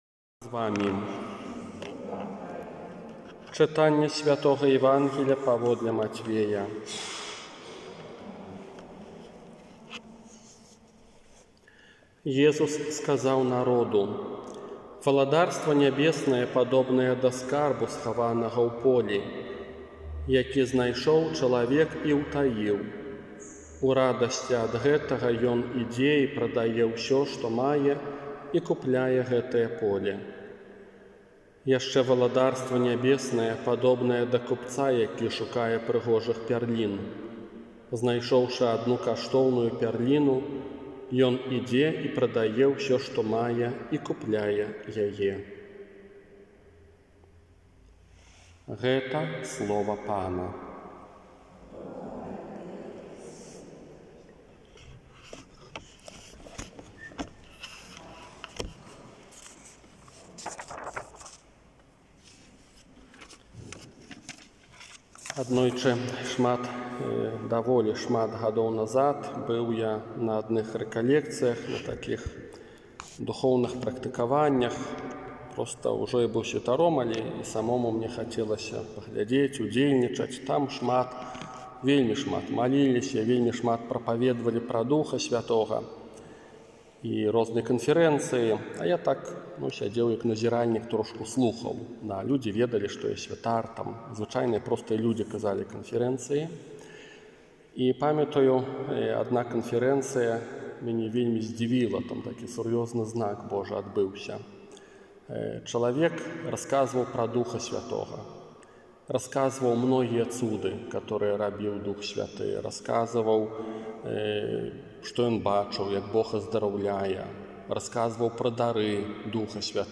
ОРША - ПАРАФІЯ СВЯТОГА ЯЗЭПА
Казанне на сямнаццатую звычайную нядзелю
Мудрасць_Божая_казанне.m4a